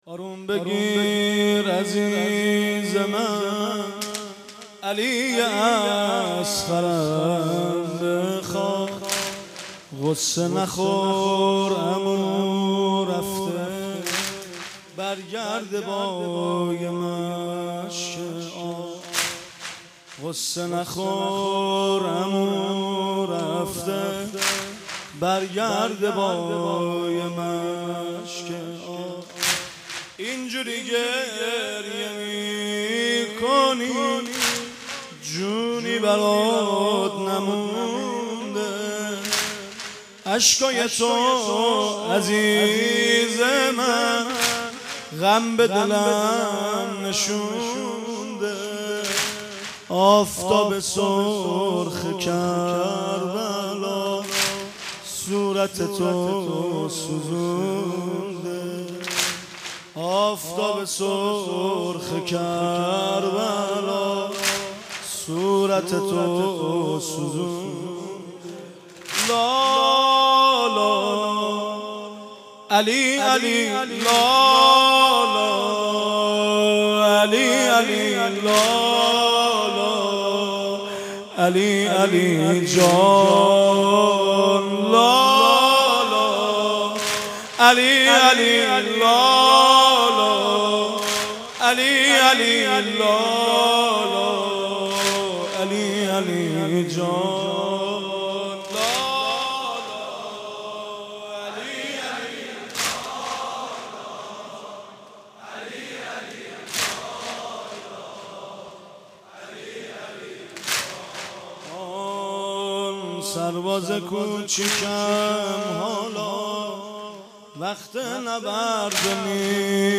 مراسم عزاداری دهه اول محرم در حسینیه حاج همت در میدان شهدا
صوت مراسم شب هفتم محرم ۱۴۳۷ دانشگاه امیرکبیر و حسینیه حاج همت ذیلاً می‌آید: